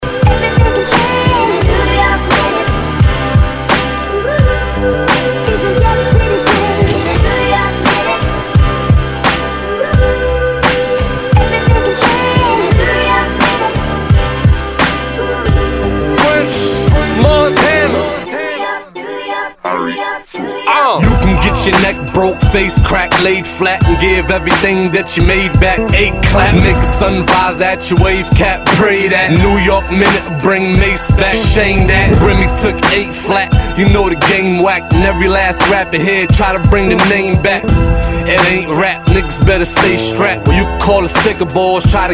Tag       EASTCOAST 　 HIP HOP